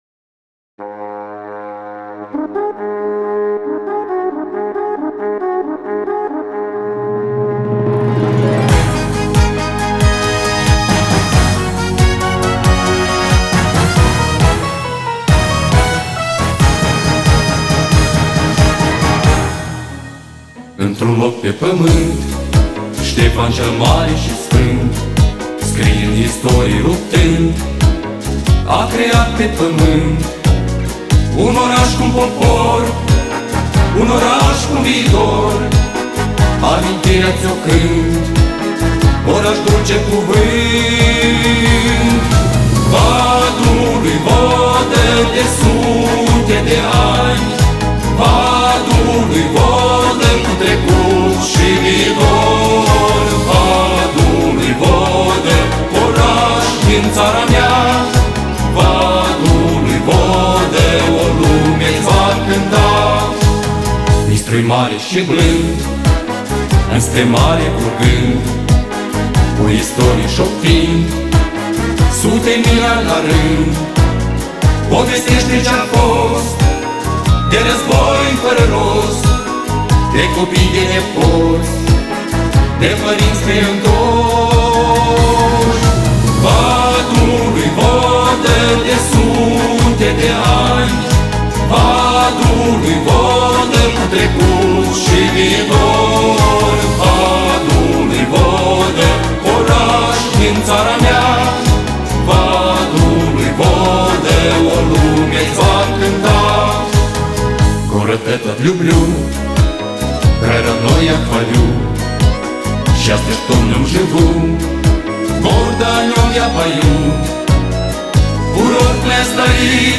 Piesa candidat pentru a fi imnul orașului Vadul lui Vodă Ansamblul vocal „Cavalerii Armoniei”
VADULUI-VODA_Imn.mp3